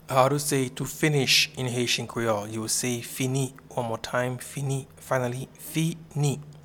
Pronunciation and Transcript:
Finish-in-Haitian-Creole-Fini.mp3